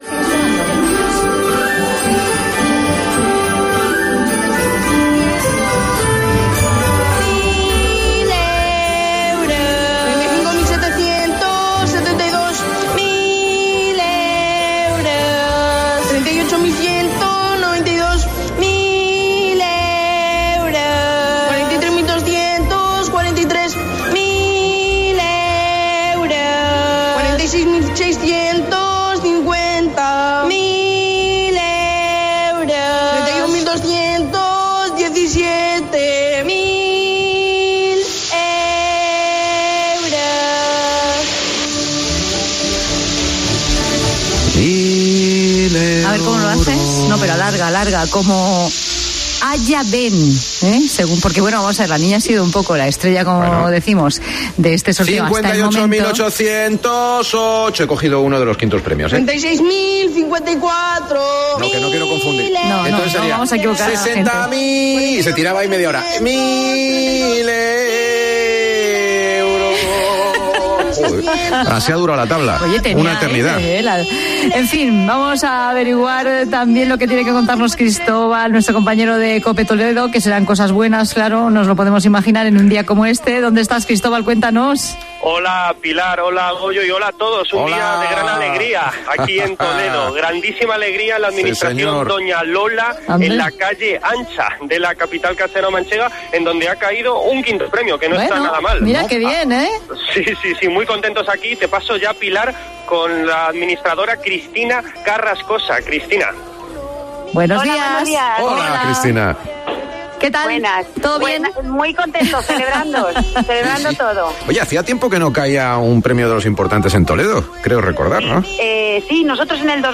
Escucha este momento del programa especial de la Cadena COPE sobre el Sorteo Extraordinario de la Lotería de Navidad